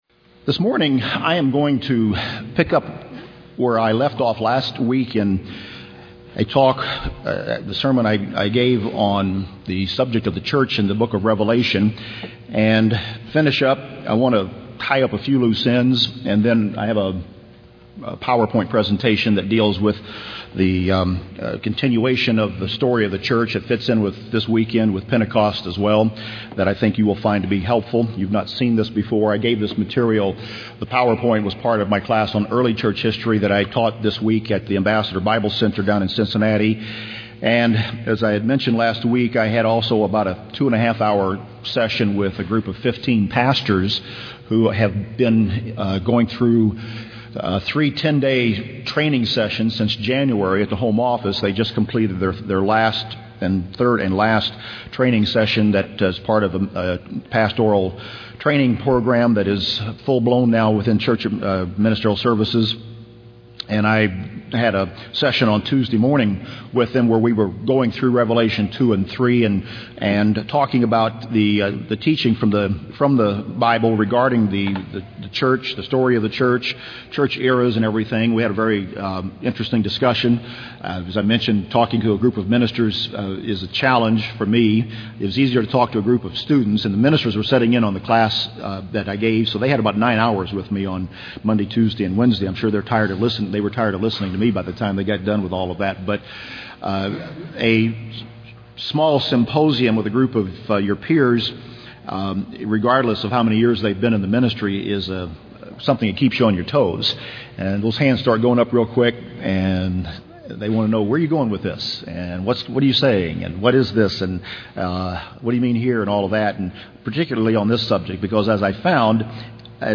(conclusion) UCG Sermon Transcript This transcript was generated by AI and may contain errors.